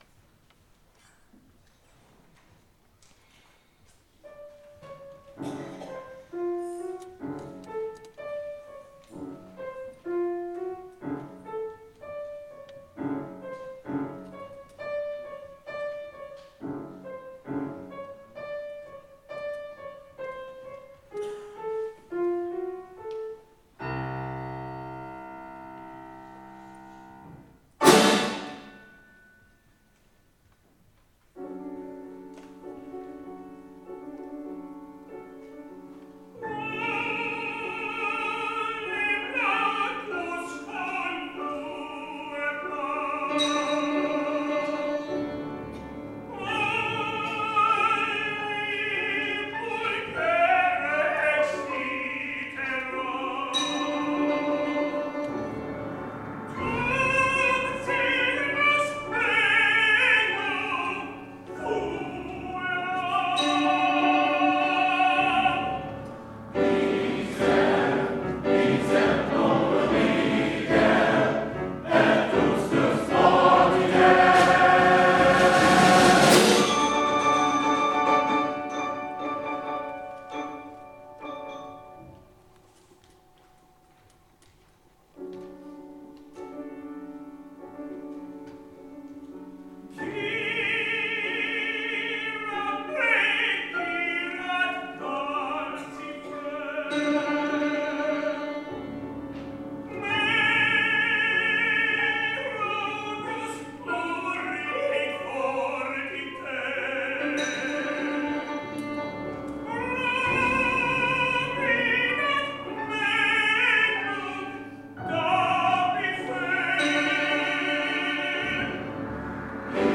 our May 4th concert
Olim lacus colueram    Tenor and Men